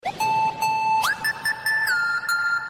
File Category : Free mobile ringtones > > Sms ringtones